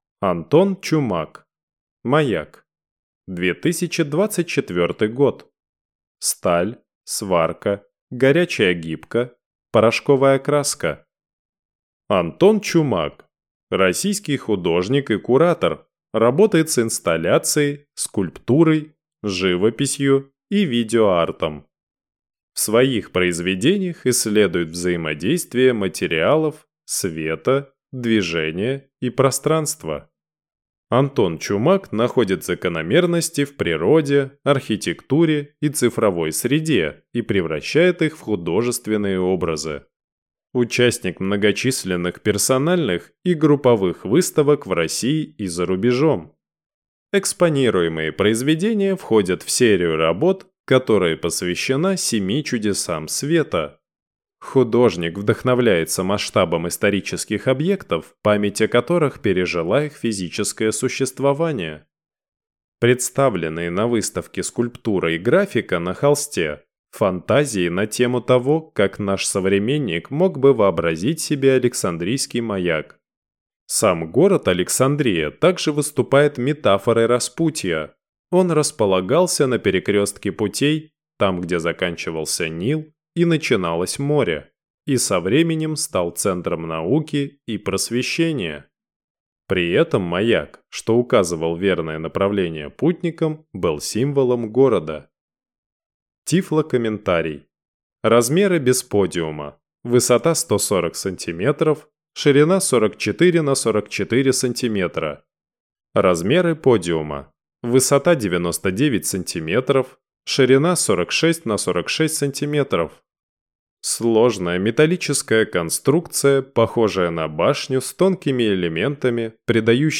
Тифлокомментарий к картине Антона Чумака "Маяк"